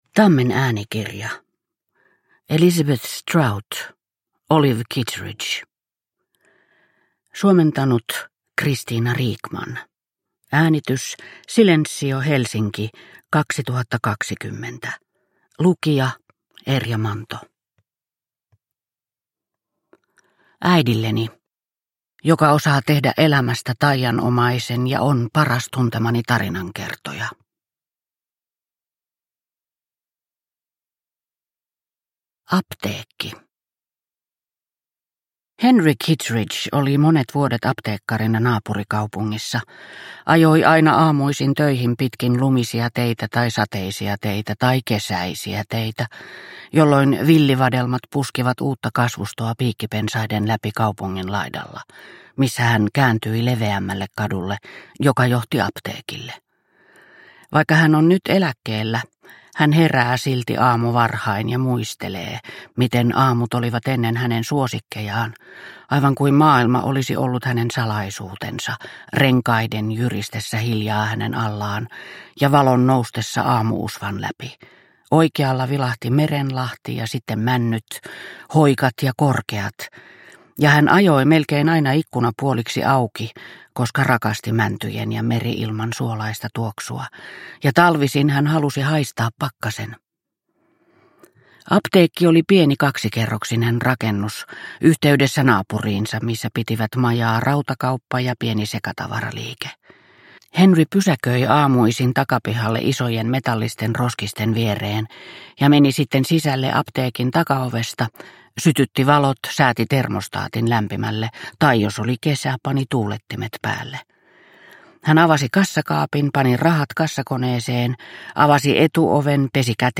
Olive Kitteridge – Ljudbok – Laddas ner